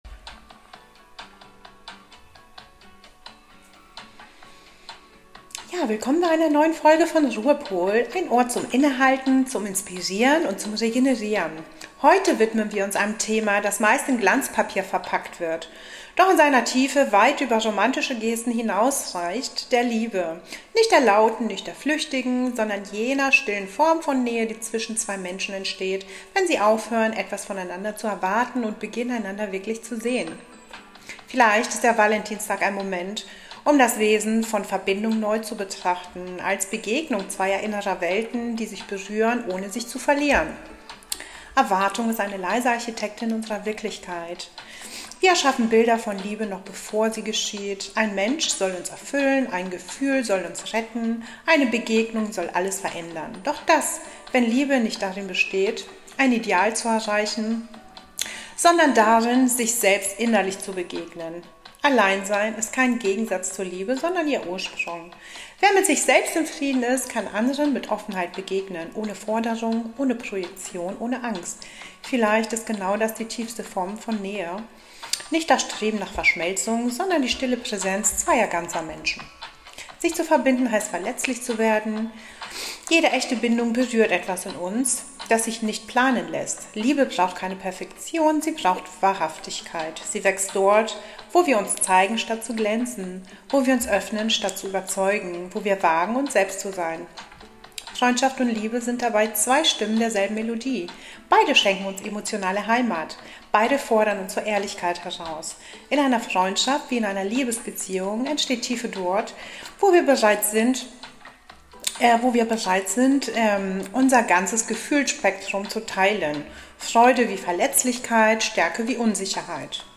Philosophisch, ruhig, ehrlich – ein Impuls zum Nachdenken über das, was bleibt, wenn Worte und Symbole verblassen.